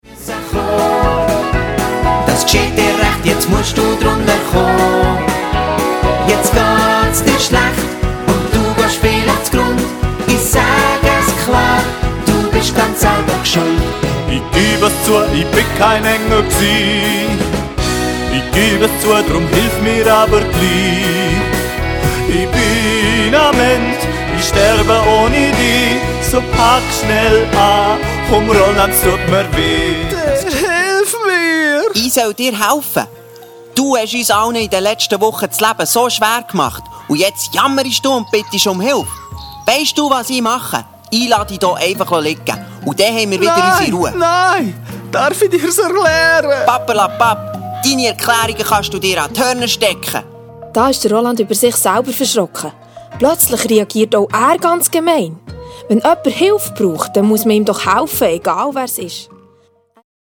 Minimusical für Grundschule und 1./2. Klasse